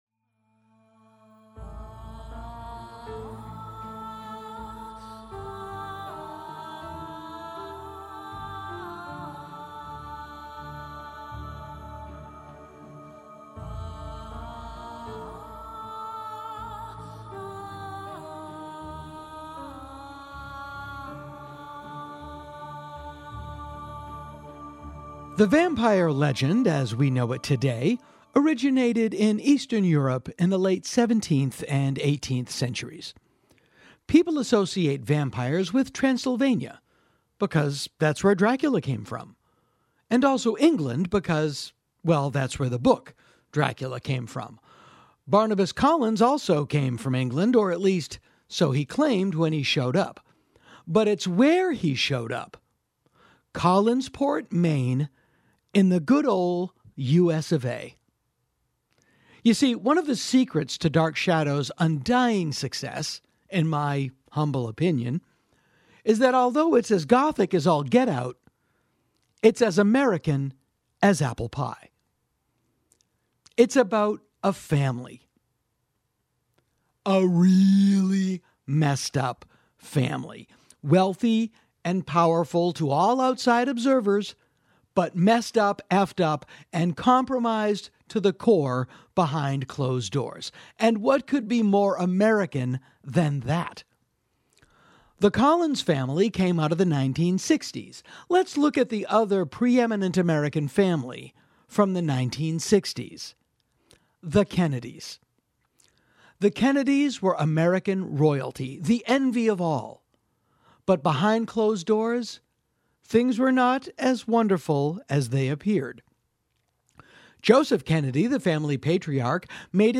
The Dark Shadows theme is performed here by Valentine Wolfe.